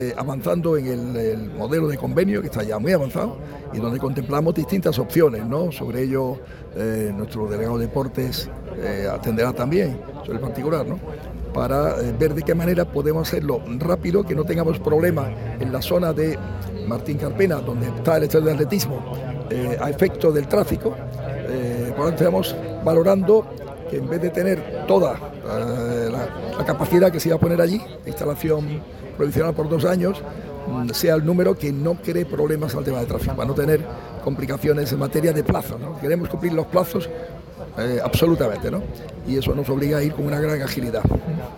En este caso, el alcalde de Málaga, Francisco De La Torre, ha atendido a los medios de comunicación. Ha sido en Kaleido Málaga Port, situado en el Palmeral de las Sorpresas, sede de la presentación oficial de la VII Málaga Sailing Cup. El motivo de la comparecencia ha sido para responder, entre otras cuestiones, sobre la solicitud de la Fiscalía en el ‘Caso Al Thani’ y también en cuanto al traslado del club boquerón al estadio Ciudad de Málaga durante la remodelación de la actual casa del Málaga CF en Martiricos.